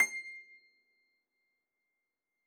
53j-pno22-C5.wav